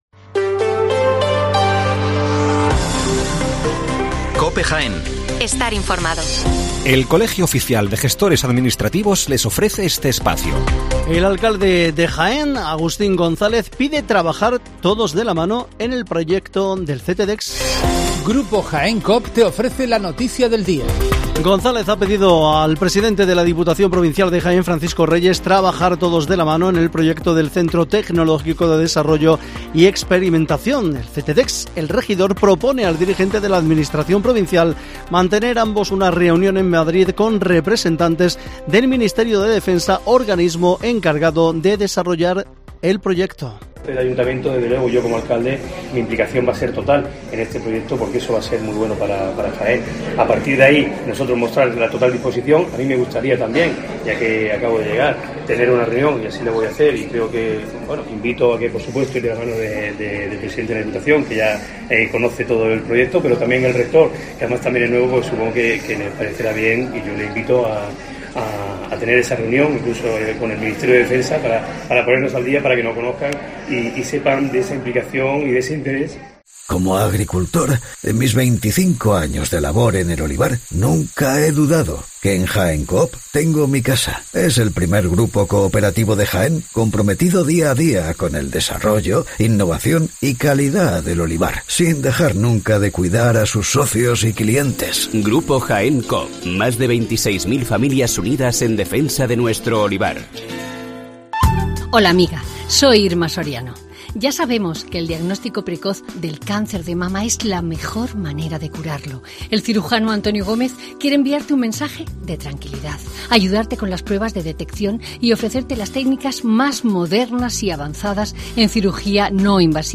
Las noticias matinales en Herrera en COPE 7:55 horas